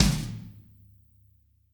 tom6.ogg